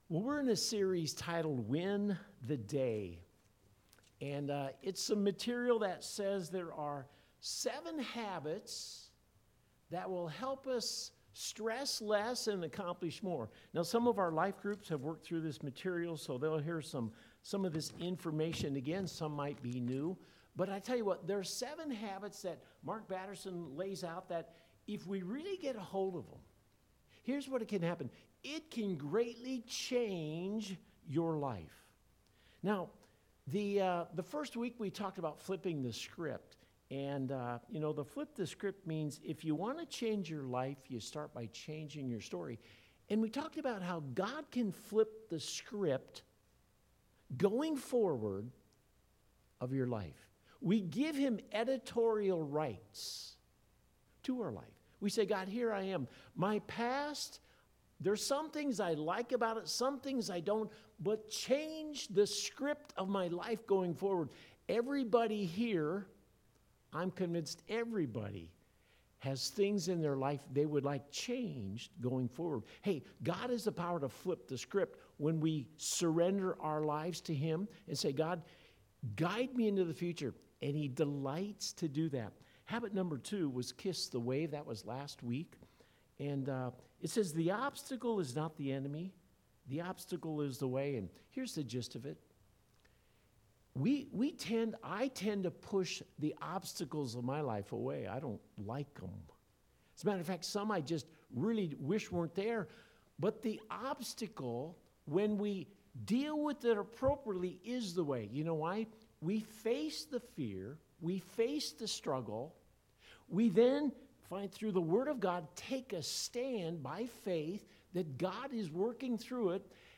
4-18-21-Eat-the-Frog-Early-Service.mp3